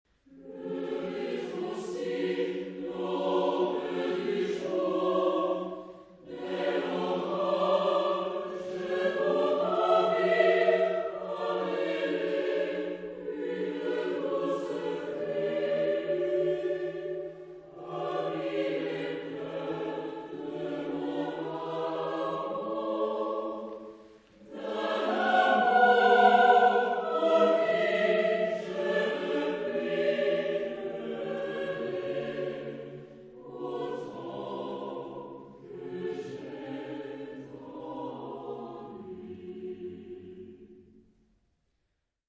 Chansons et madrigaux, chœur à trois ou quatre voix mixtes et piano ad libitum